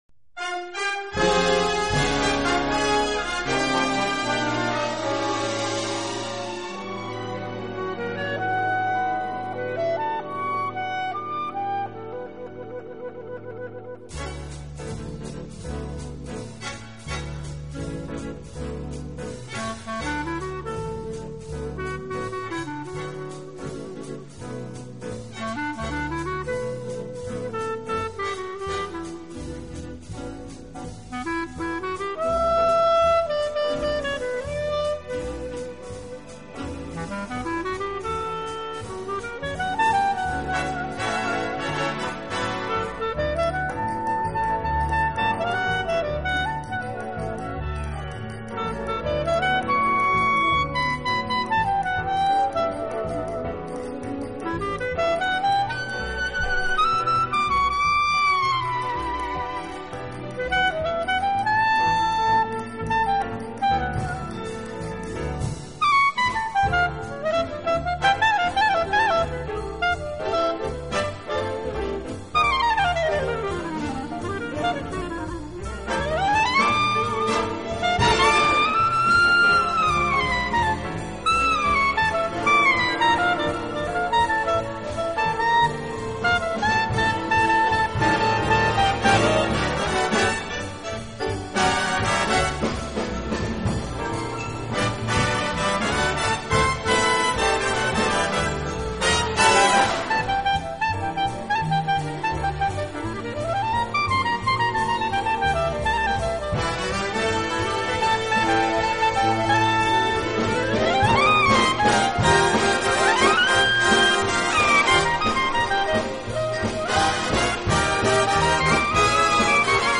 分类：发烧/爵士
以20世纪30-40年代Big Band 大乐团组合的方式，重现了美好时光的珍贵回忆。